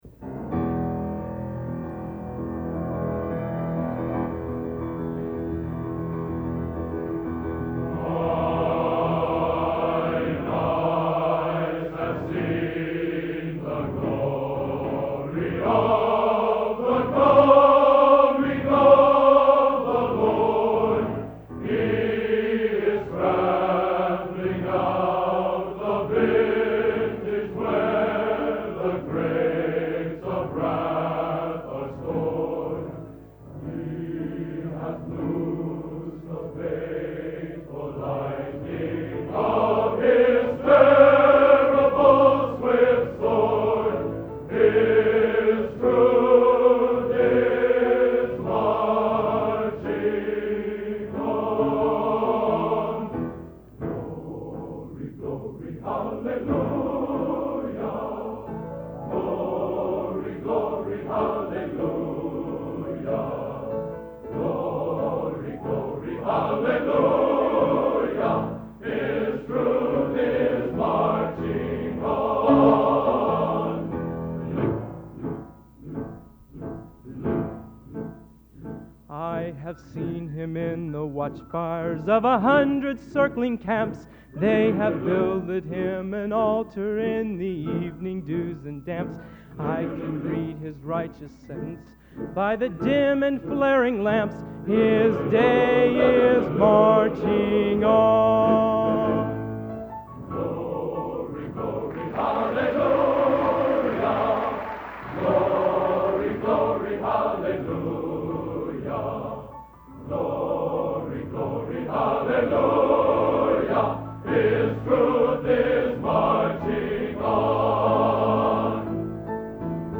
Location: West Lafayette, Indiana
Genre: Patriotic | Type: End of Season